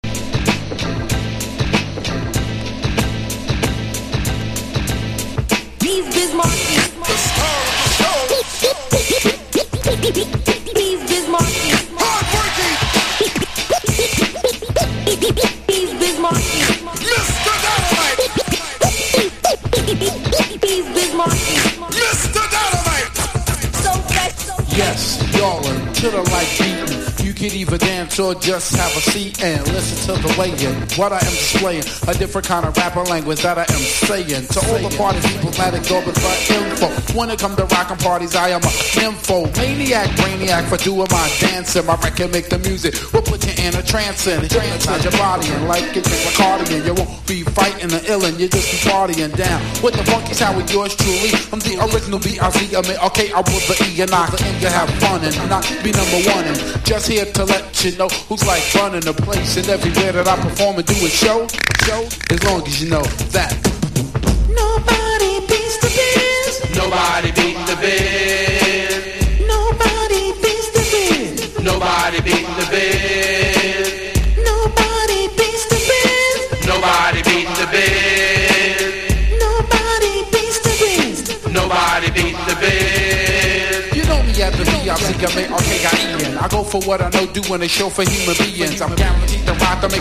力強いロックナンバーでヒット！